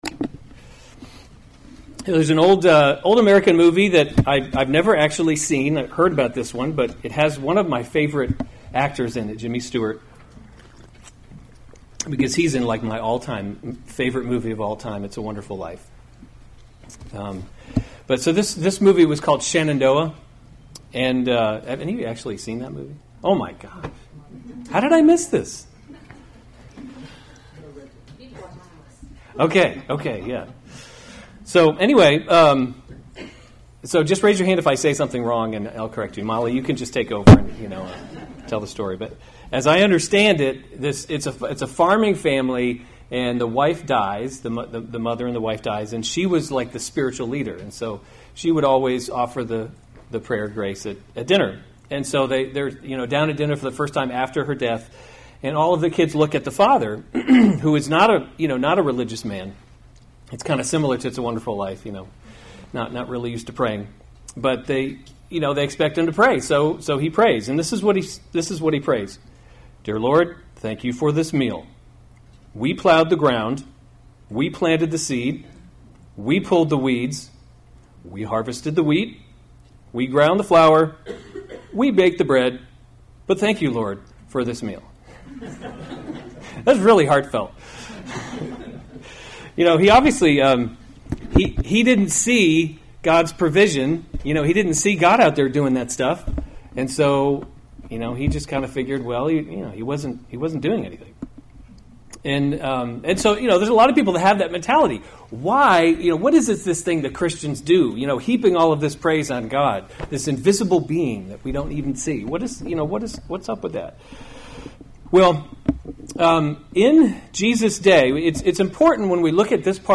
July 22, 2017 Lord’s Prayer: Kingdom Come series Weekly Sunday Service Save/Download this sermon Matthew 6:11 Other sermons from Matthew 11 Give us this day our daily bread, [1] (ESV) Footnotes [1] […]